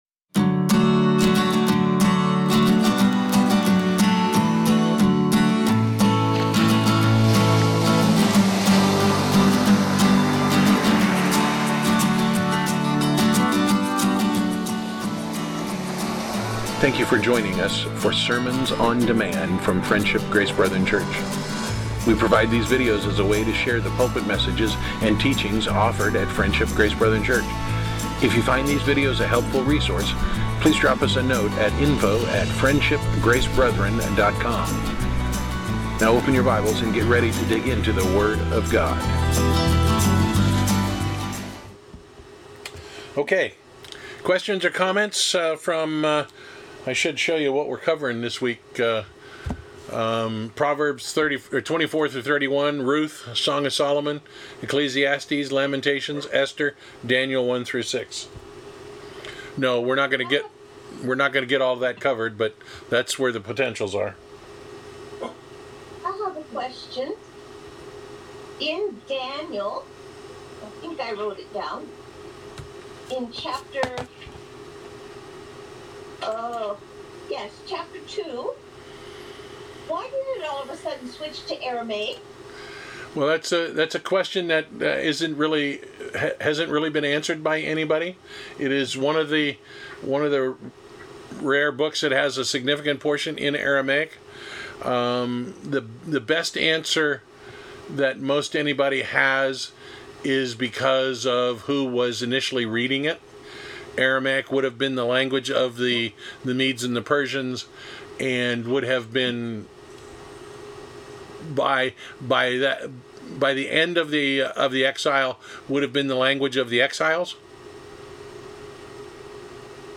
Topic: Weekly Bible Reading Discussion